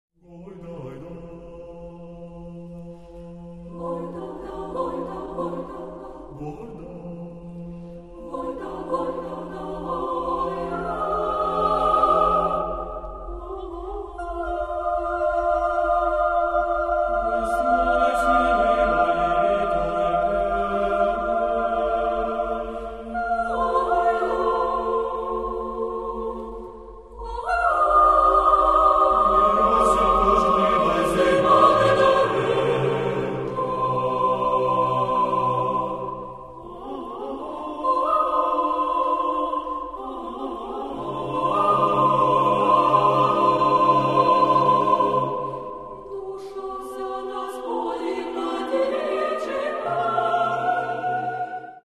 Каталог -> Класична -> Хорове мистецтво